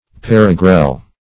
paragrele - definition of paragrele - synonyms, pronunciation, spelling from Free Dictionary
Search Result for " paragrele" : The Collaborative International Dictionary of English v.0.48: Paragrele \Pa`ra`gr[^e]le"\, n. [F., fr. parer to guard + gr[^e]le hail.] A lightning conductor erected, as in a vineyard, for drawing off the electricity in the atmosphere in order to prevent hailstorms.